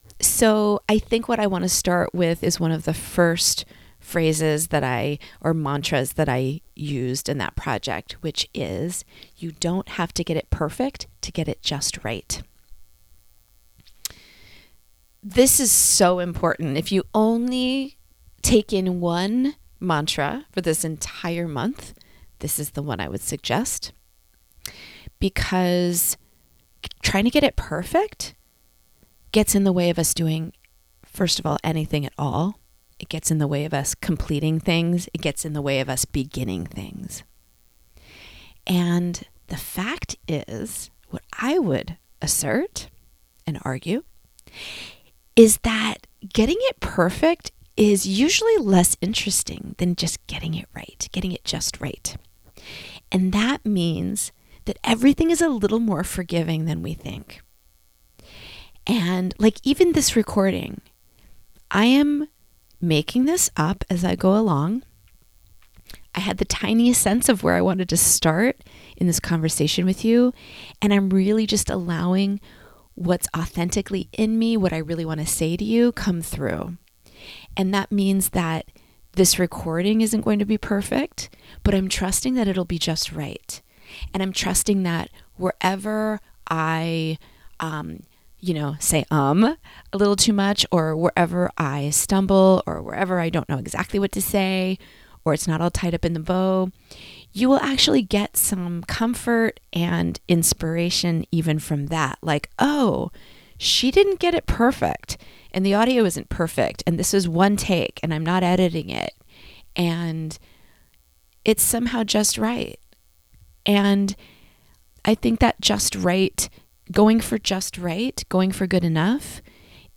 This will be a brand new collection of audio messages just for you every morning.